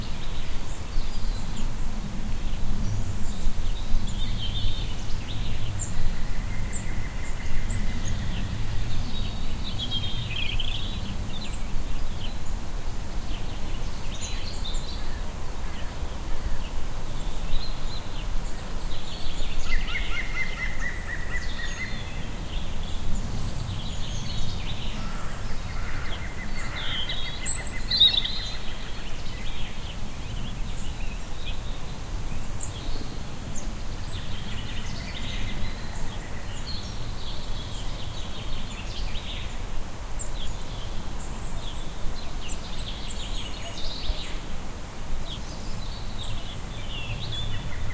Garden_nature.ogg